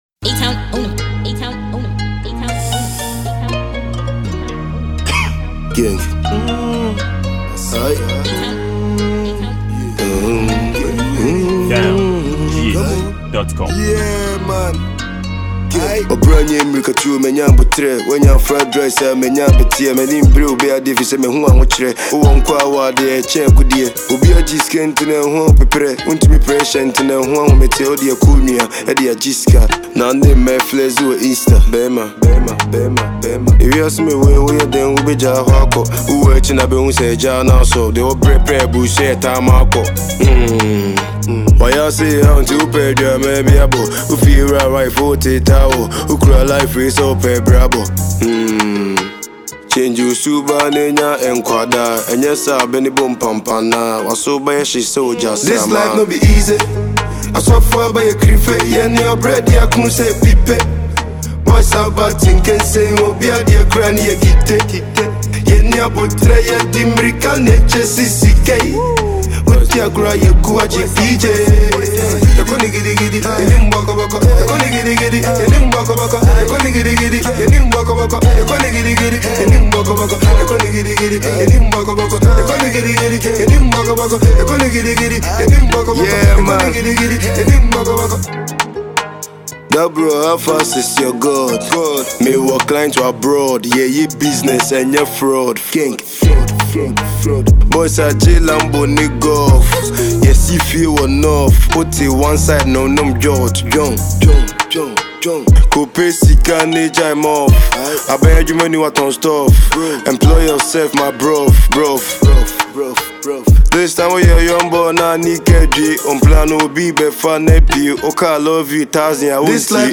Ghanaian Asakaa musician